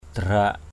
/d̪a-ra:˨˩ʔ/